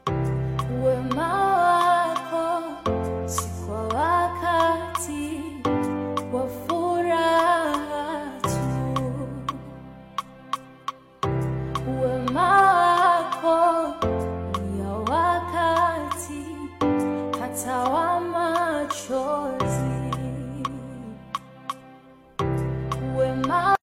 It’s like a big ol’ hug for your soul.